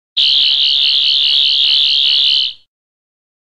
sonic-screwdriver_24752.mp3